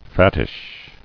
[fat·tish]